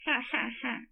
4 guys having a laugh